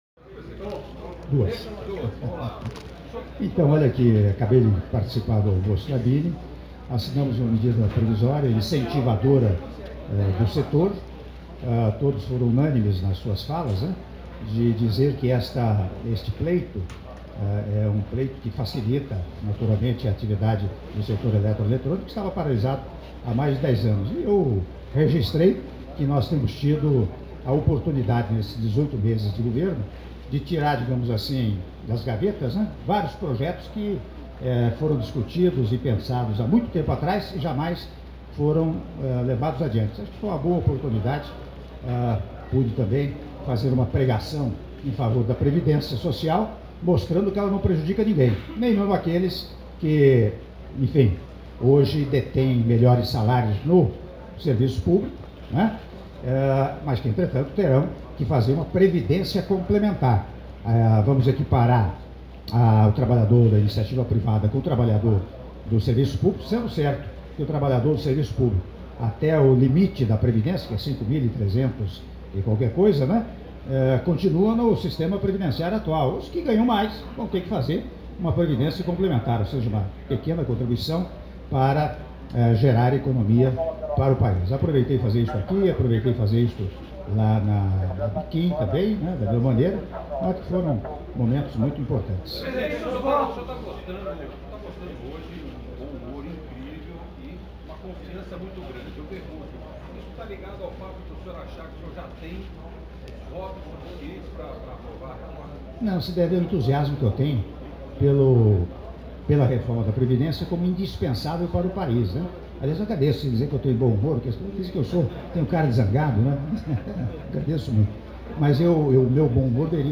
Áudio da entrevista coletiva concedida pelo Presidente da República, Michel Temer, após almoço anual da Indústria Elétrica e Eletrônica 2017 - São Paulo/SP (03min24s) — Biblioteca